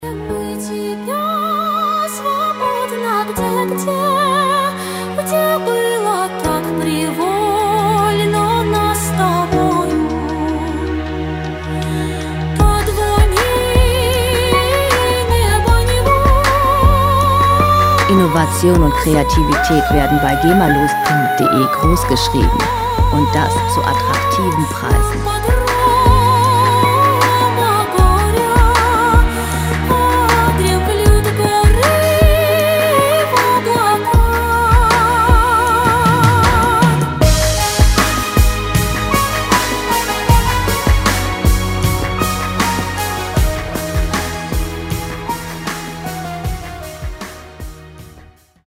• Classical Crossover
mit modernen Beats und slawischem Gesang